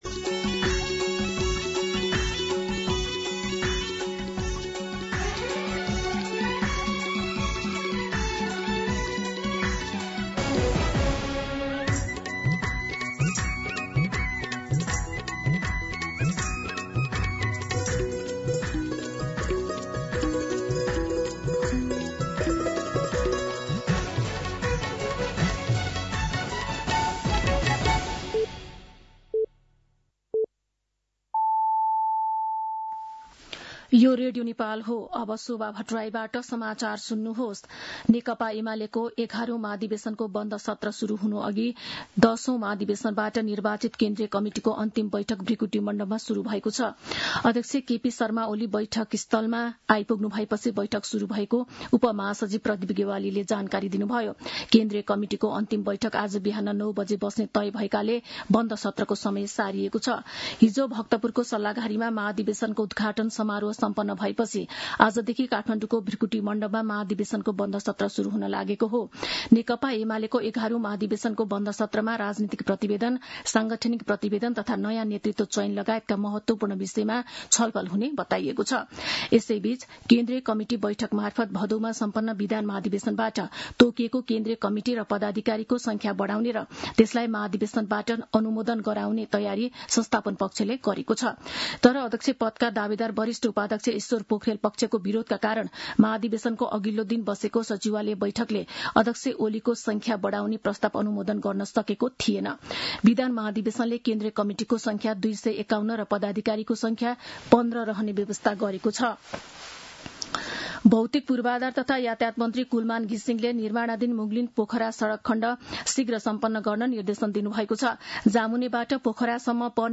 मध्यान्ह १२ बजेको नेपाली समाचार : १८ पुष , २०२६